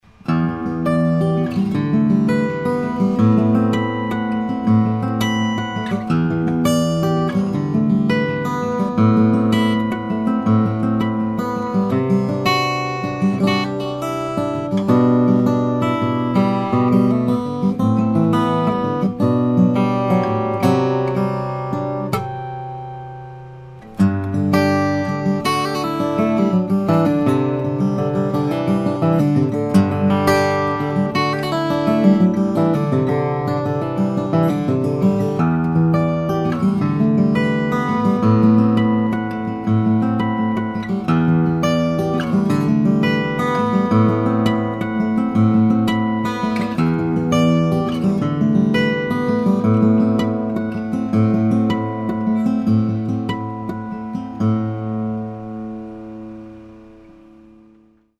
Here we have a like new Goodall RGCC, Rosewood Grand Concert Cutaway with a Cedar top.
The voice has the Goodall overtones and shimmer in spades. It’s very responsive and has wonderful sustain as well.